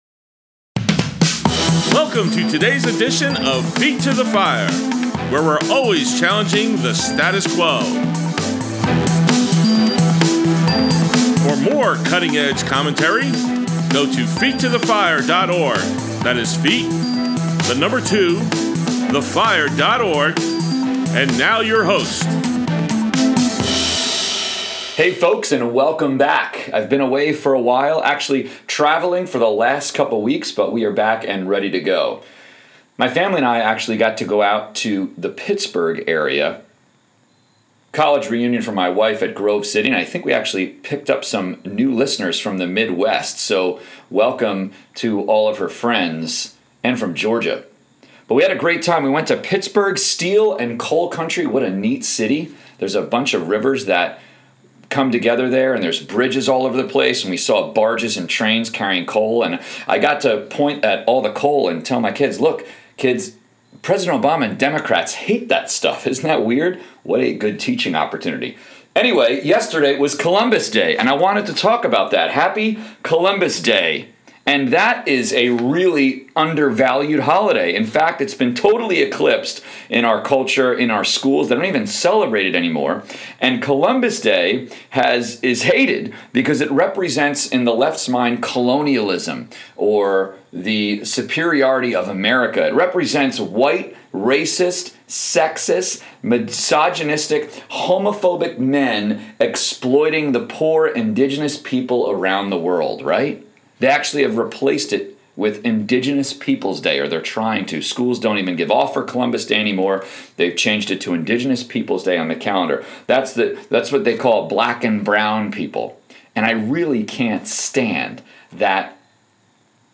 | Feet to the Fire Politics: Conservative Talk Show